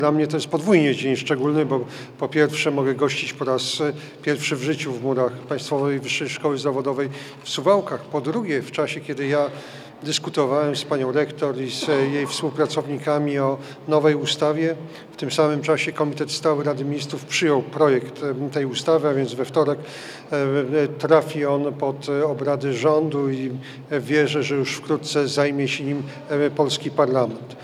Jarosław Gowin, wicepremier i minister nauki i szkolnictwa wyższego gościł dziś w Państwowej Wyższej Szkole Zawodowej w Suwałkach. Spotkał się ze studentami i kadrą, zwiedzał uczelniane pracownie, mówił o założeniach nowej ustawy o szkolnictwie wyższym i przekazał szkole prawie półtora miliona złotych na organizację zajęć praktycznych.